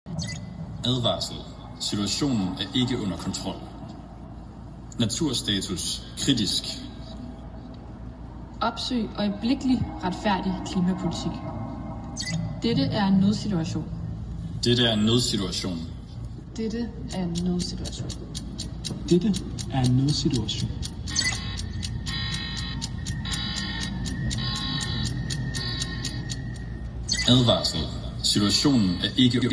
Imens aktivisterne spærrer området foran Axelborg af med skriggult afspærringsbånd, bliver den ellers stille morgen pludselig afbrudt af en en dyb, dystopisk lyd, der mest af alt giver associationer til en katastrofefilm, hvor en dødelig virus har sendt verden i undtagelsestilstand.
Fra højttaleren lyder det:
Lyd-fra-aktionen--2.m4a